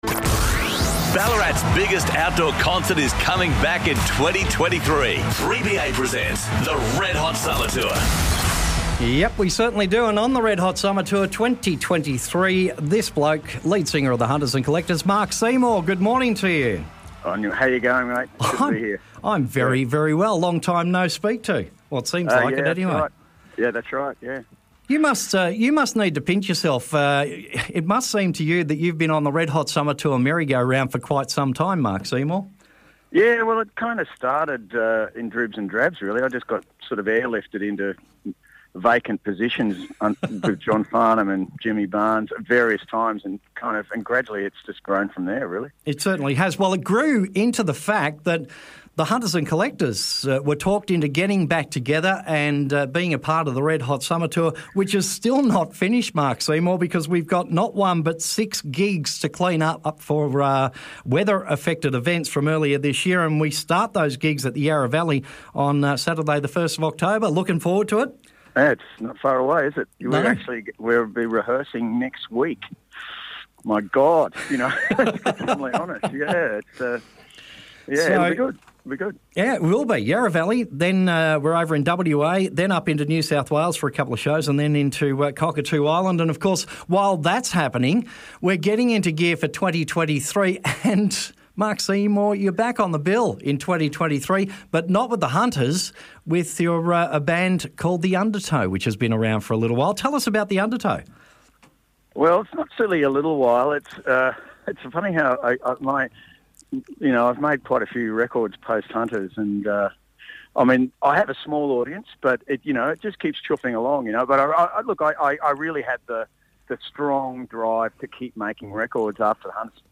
Mark Seymour Chat